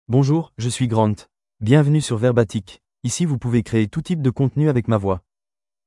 Grant — Male French (France) AI Voice | TTS, Voice Cloning & Video | Verbatik AI
Grant is a male AI voice for French (France).
Voice sample
Listen to Grant's male French voice.
Grant delivers clear pronunciation with authentic France French intonation, making your content sound professionally produced.